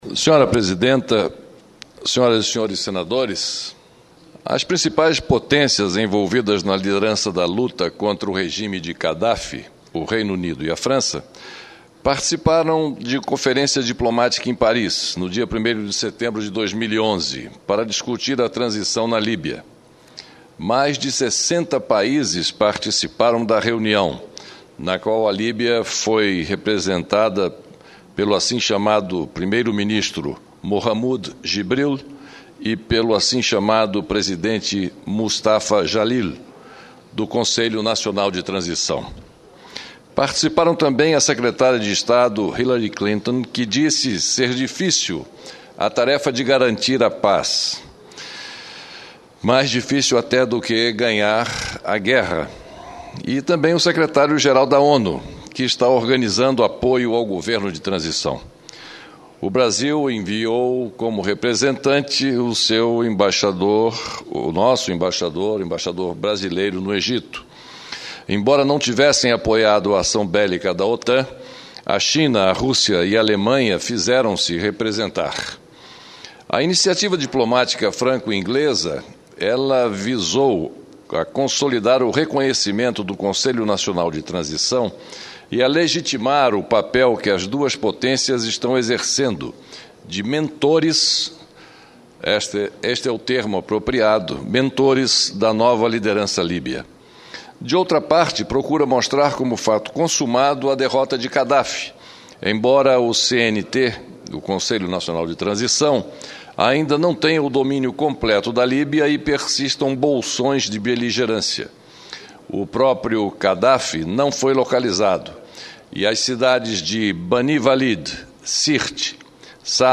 O senador Fernando Collor (PTB-AL) comentou a reunião das principais potências mundiais em Paris, dia 1º de setembro, para discutir a transição na Líbia, após a derrubada do antigo governo. O encontro, que teve a liderança de França e Inglaterra, reuniu mais de 6O países.